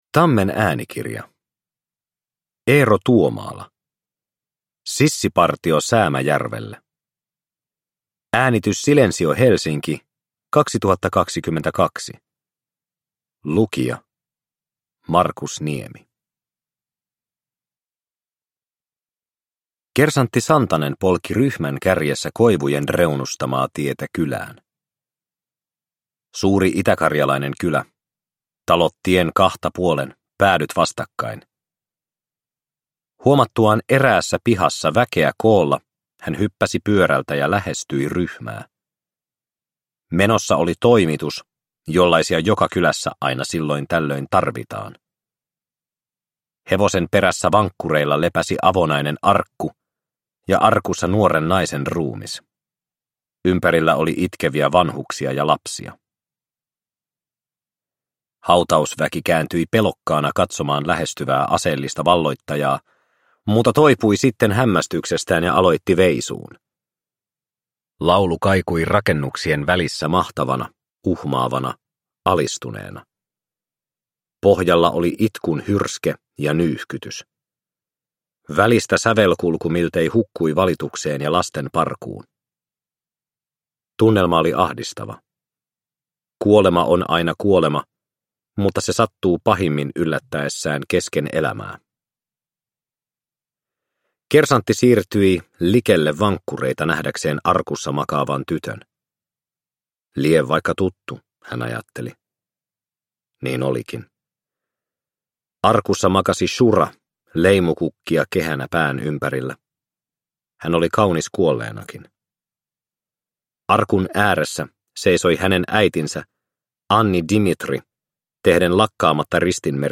Sissipartio Säämäjärvelle – Ljudbok – Laddas ner